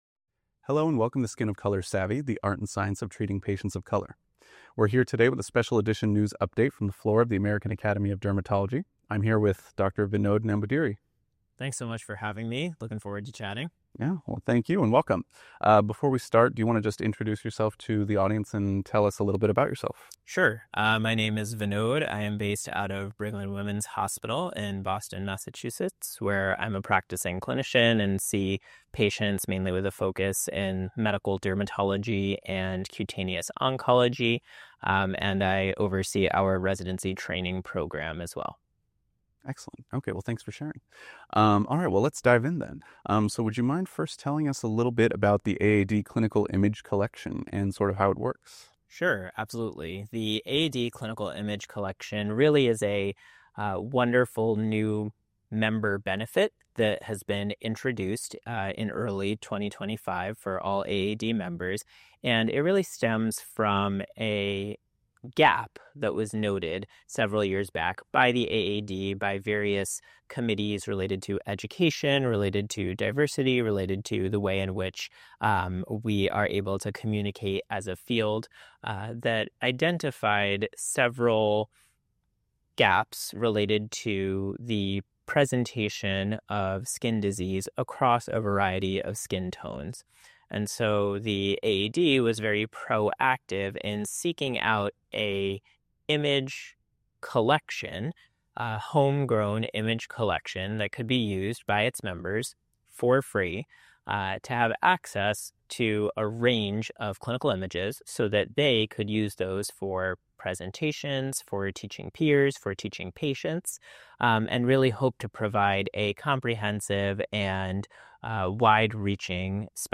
on the floor of the 2025 American Academy of Dermatology (AAD) Annual Meeting